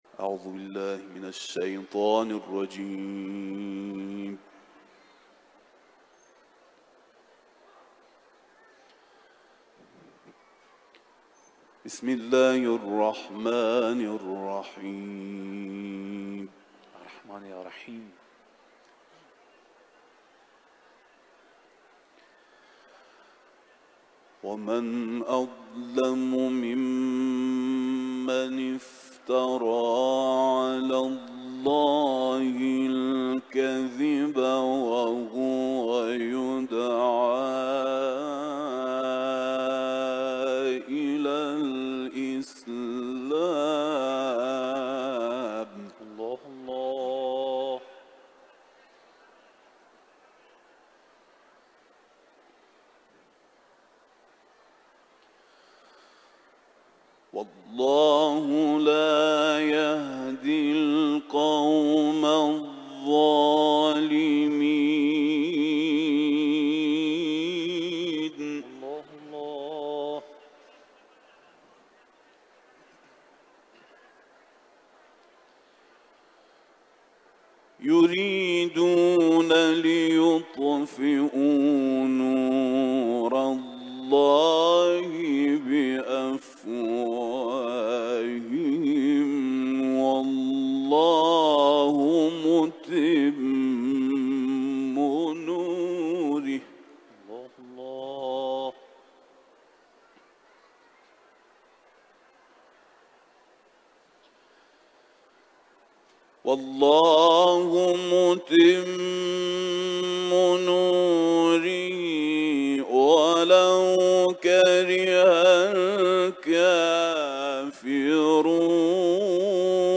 İranlı kâri
Kur’an-ı Kerim tilaveti